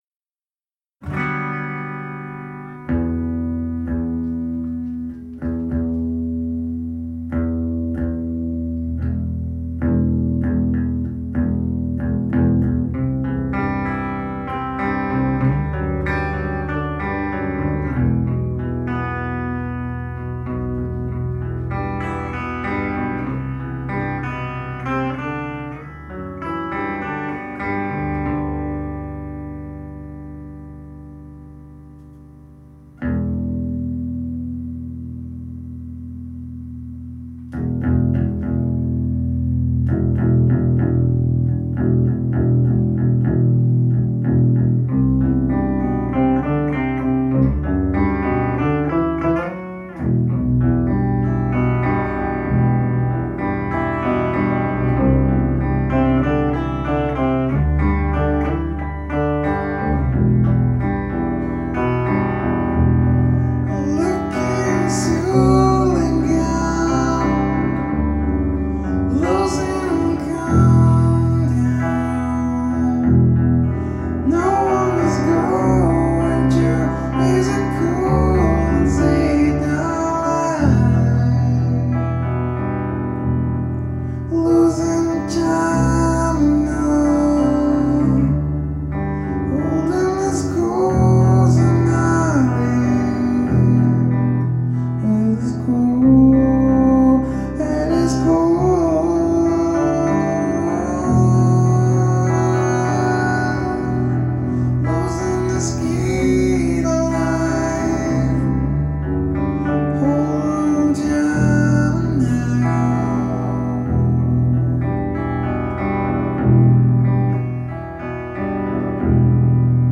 where: Chez BAM
Jam